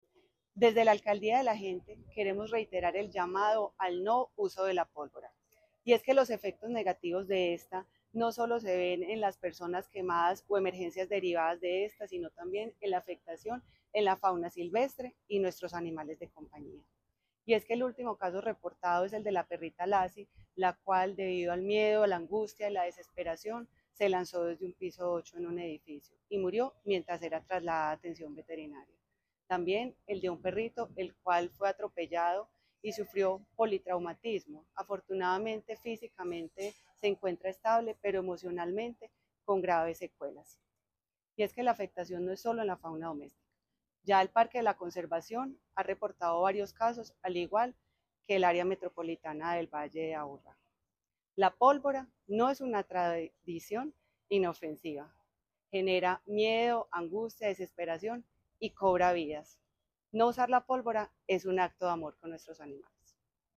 Declaraciones subsecretaria de Protección y Bienestar Animal, Elizabeth Coral La Alcaldía de Medellín reiteró el llamado a los ciudadanos a evitar el uso de pólvora durante las celebraciones decembrinas, ante el grave impacto que implica para los animales de compañía.
Declaraciones-subsecretaria-de-Proteccion-y-Bienestar-Animal-Elizabeth-Coral.mp3